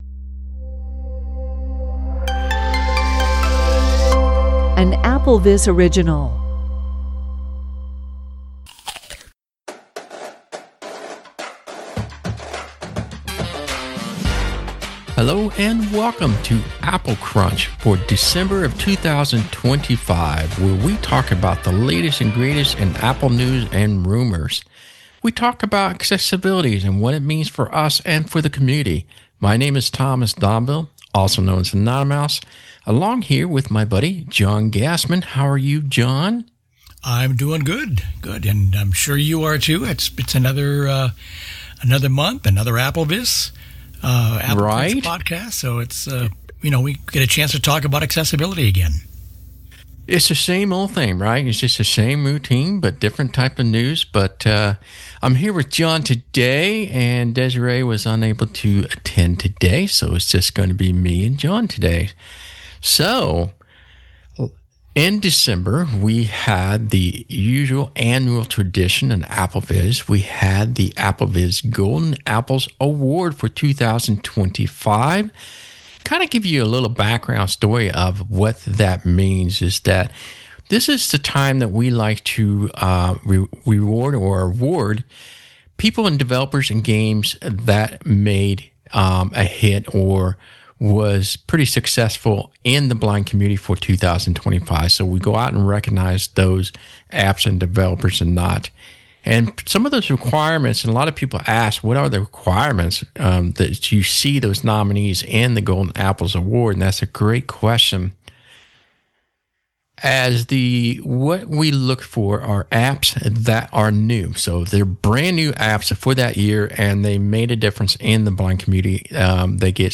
From there, the conversation shifts to Apple’s own 2025 App Store Awards, focusing on the Cultural Impact category. Art of Fauna and Be My Eyes are highlighted as winners, with discussion around why Apple may have chosen to spotlight these apps as examples of accessibility, inclusion, and broader social awareness.